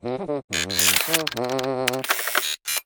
droneReloading2.wav